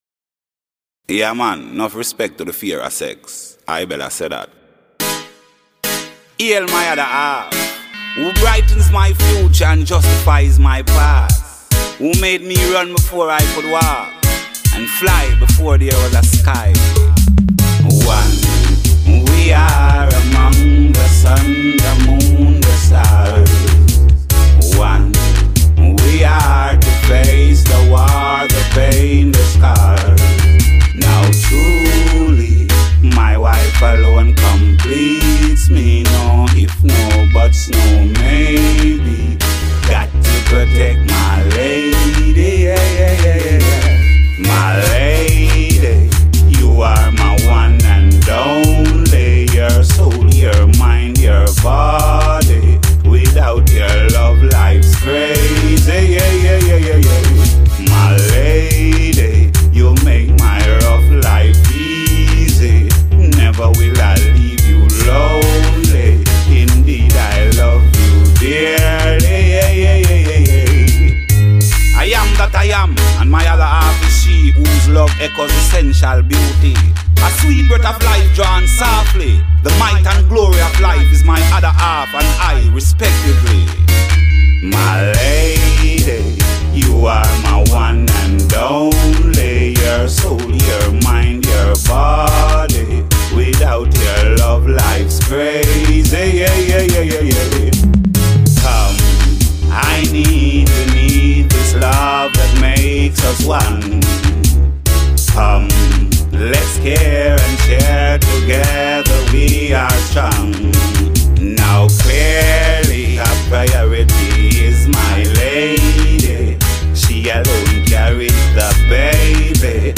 Conscious singer
on a riddim